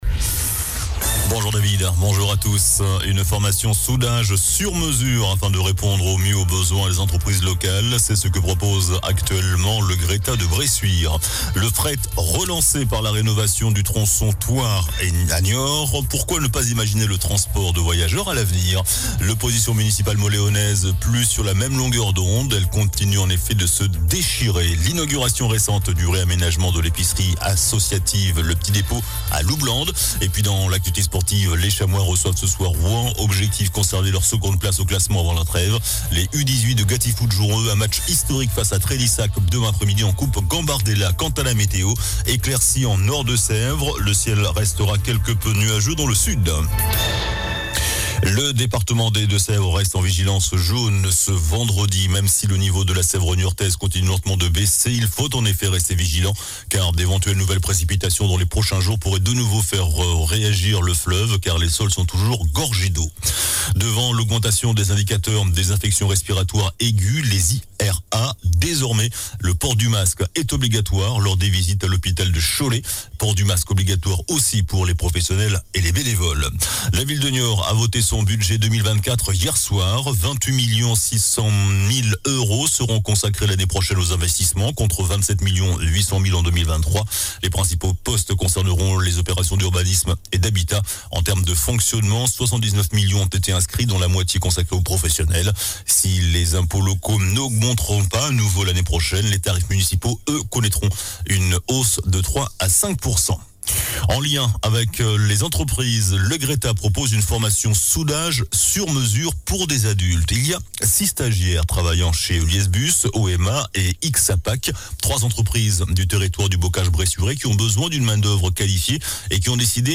JOURNAL DU VENDREDI 15 DECEMBRE ( MIDI )